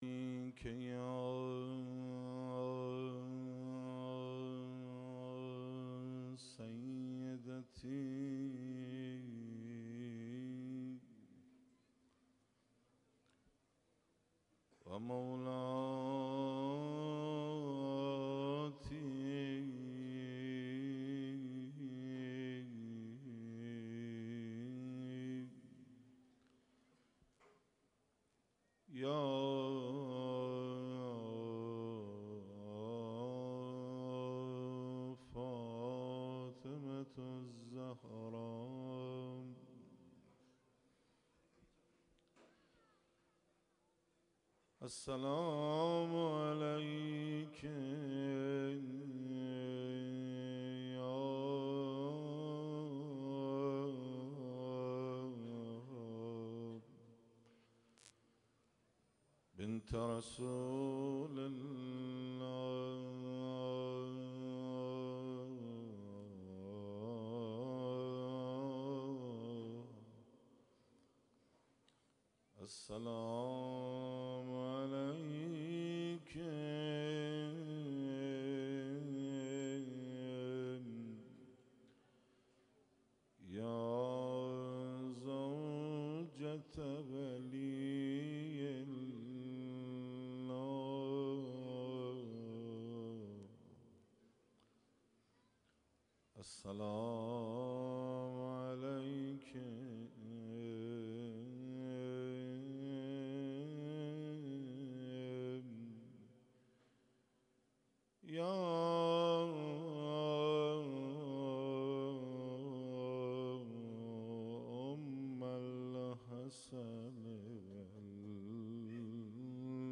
12 اسفند 95 - حسینیه تاج دارباهو - روضه - فاطمه مجموعه صفات